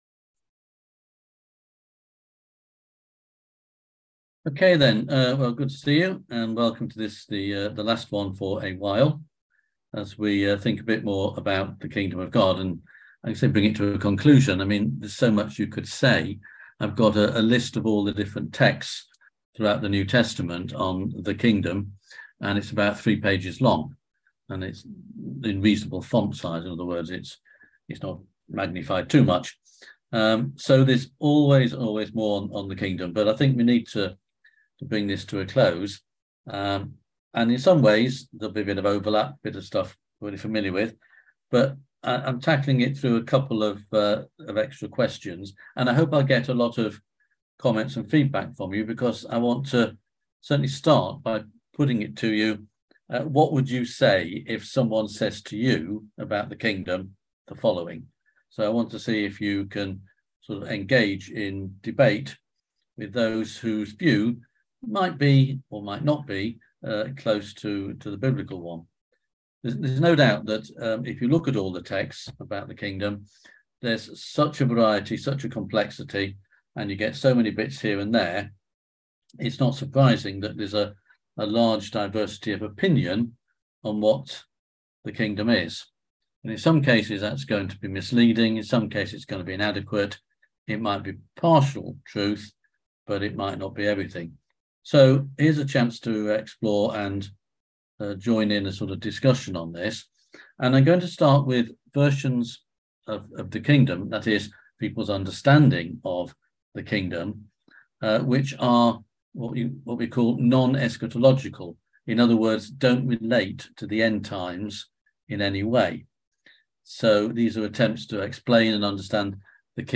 On June 29th at 7pm – 8:30pm on ZOOM